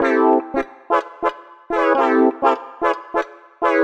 cch_synth_ness_125_Cm.wav